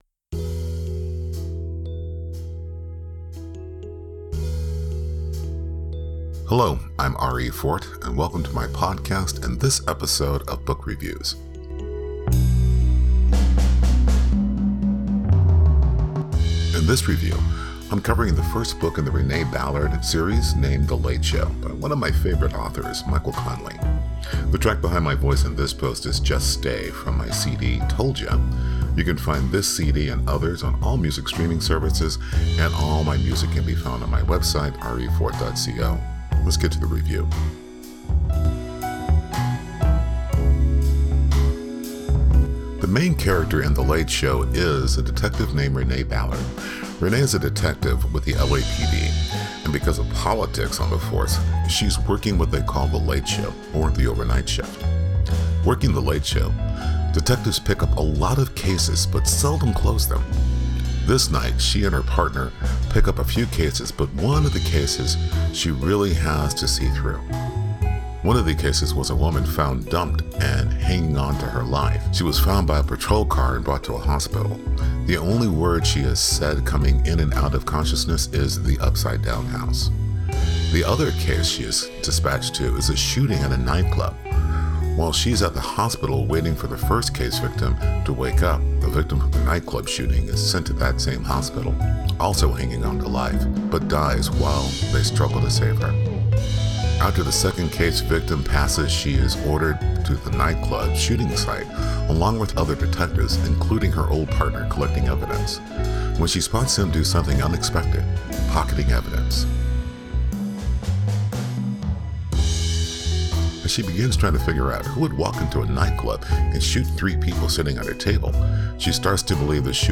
Book Review of The Late Show